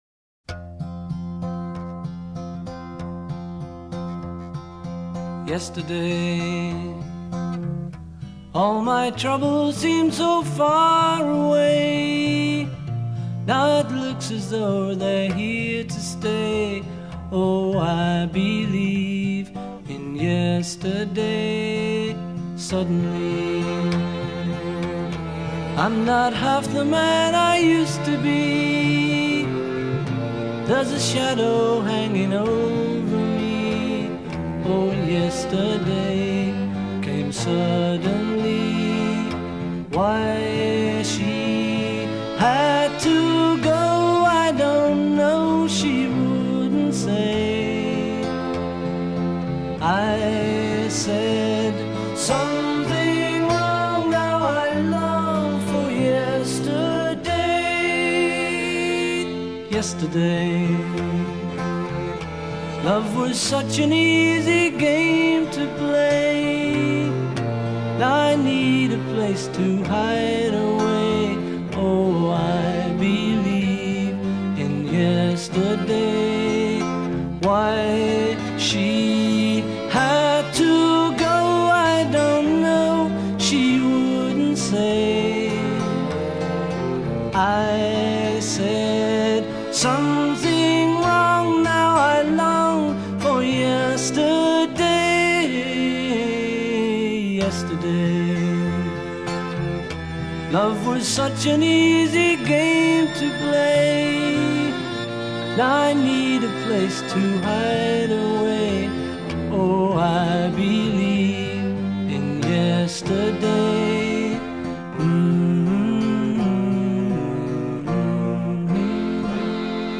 intro 0:00 4 guitar
A verse : 14 add string quartet b
A verse : 14 violin holds high note d
coda :   hum hook